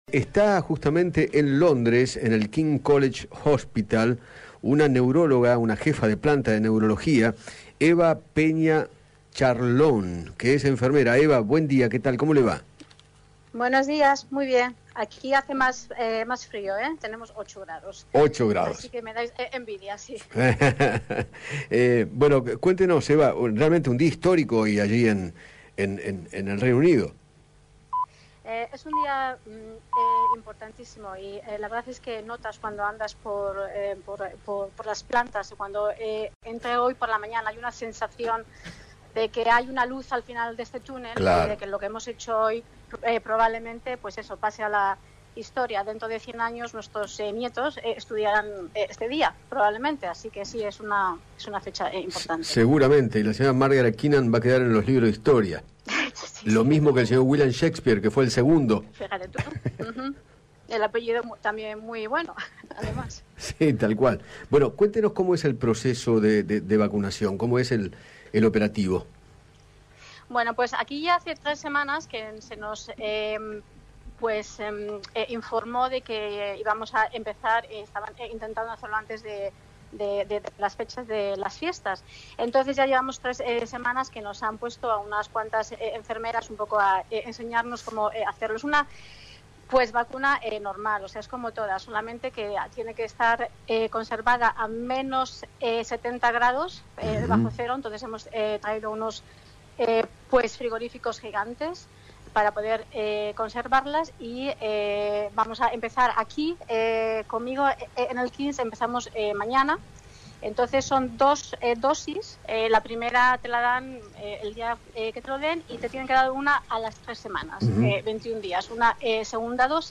dialogó con Eduardo Feinmann sobre el comienzo de la vacunación contra el coronavirus y detalló cómo es el proceso de conservación de la vacuna de Pfizer.